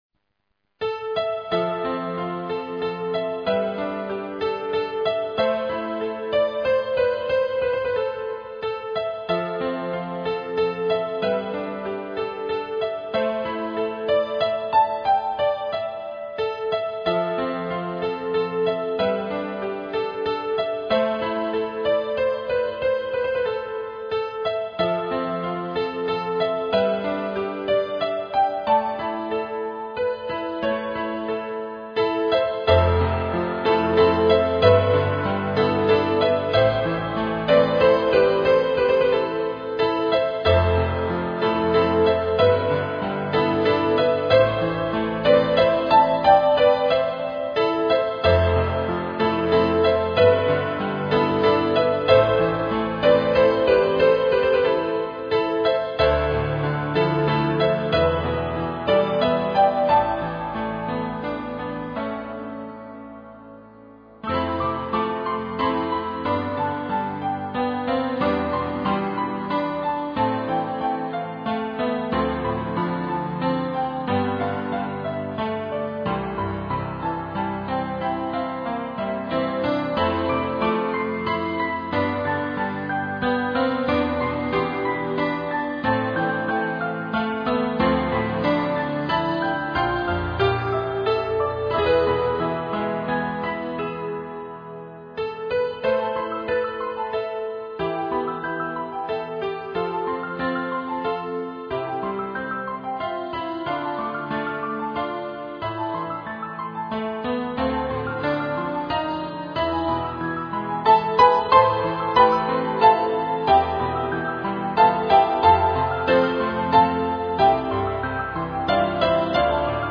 ピアノ重奏曲